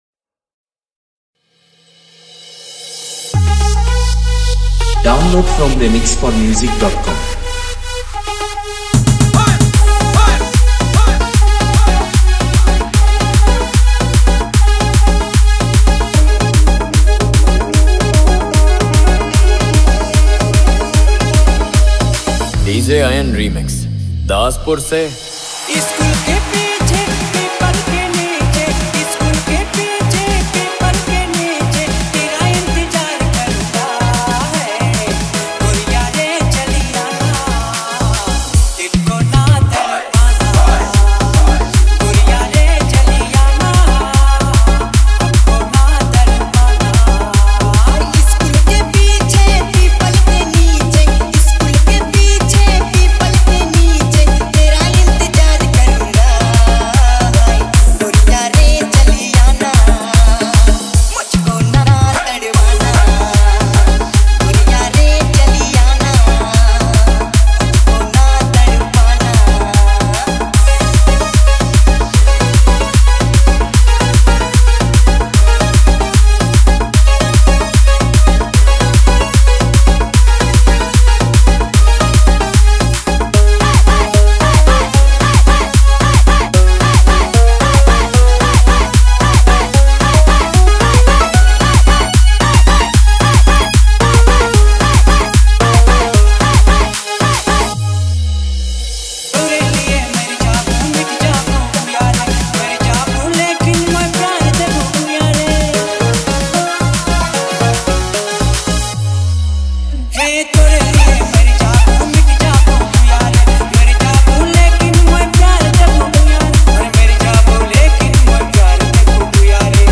Category : Others DJ Remix